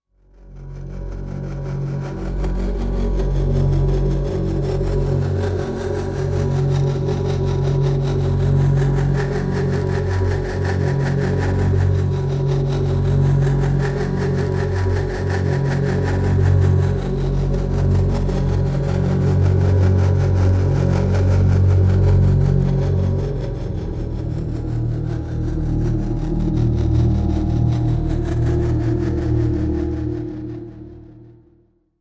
Pulsing
Empty Drone Medium Sweeping Pulse with Low Drone